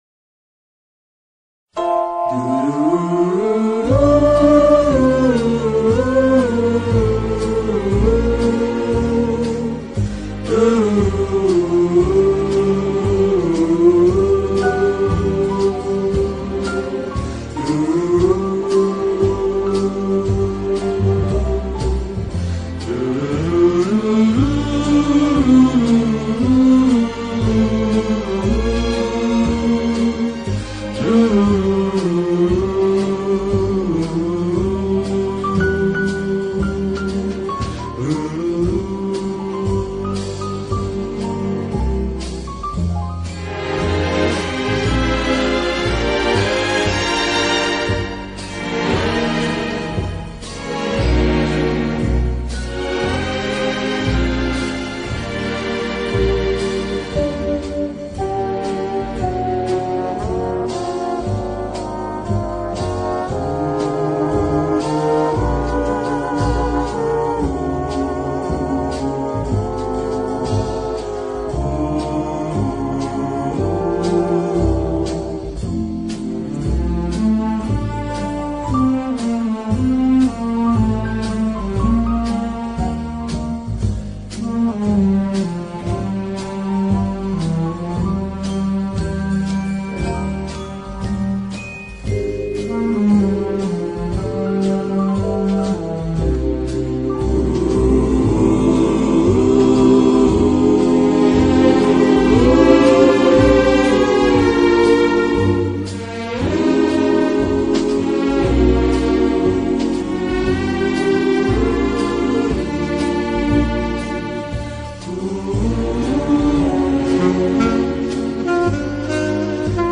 Soundtrack, Jazz